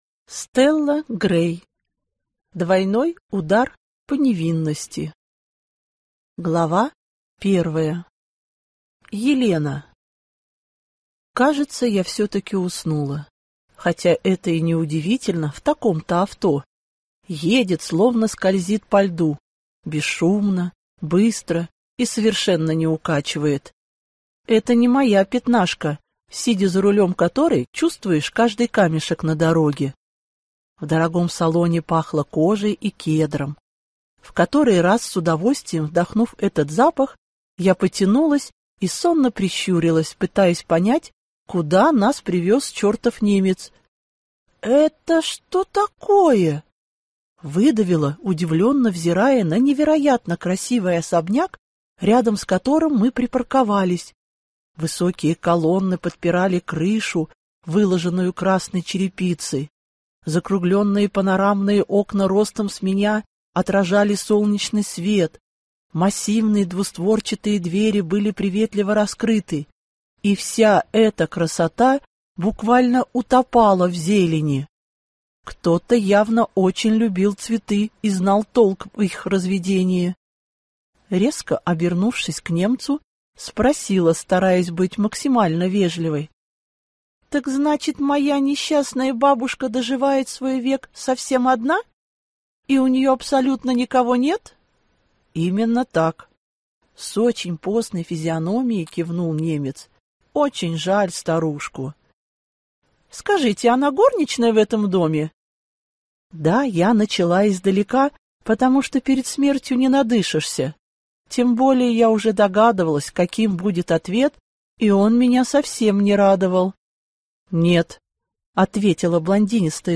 Аудиокнига Двойной удар по невинности | Библиотека аудиокниг